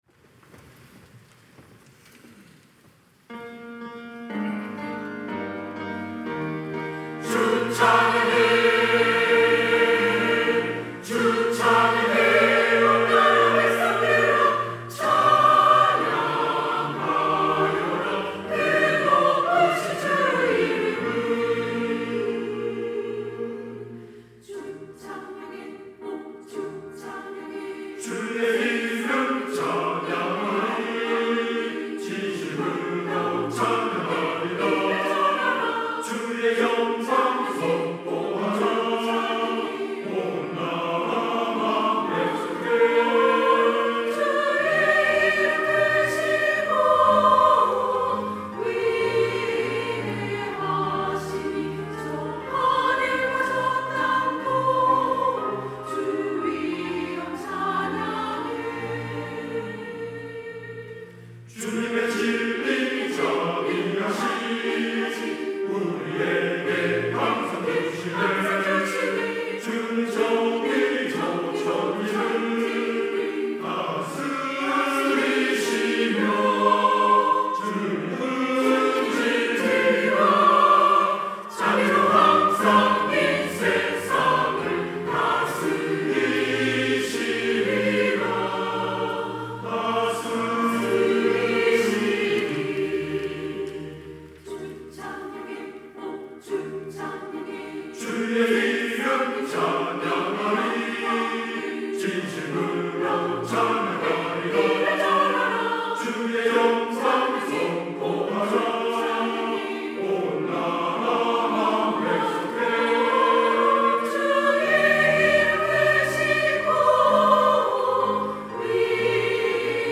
시온(주일1부) - 주 찬양해
찬양대